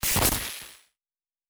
pgs/Assets/Audio/Sci-Fi Sounds/Electric/Glitch 2_06.wav at 7452e70b8c5ad2f7daae623e1a952eb18c9caab4
Glitch 2_06.wav